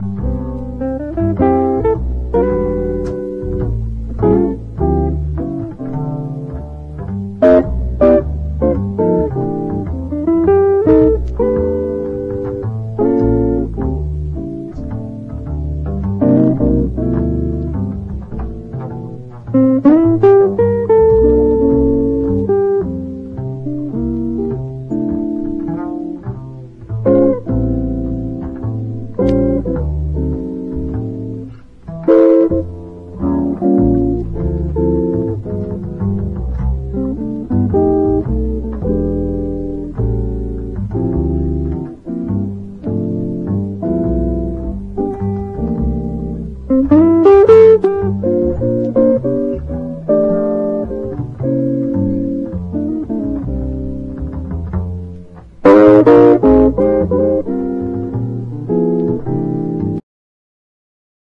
JAZZ / DANCEFLOOR / SOUL JAZZ / MOD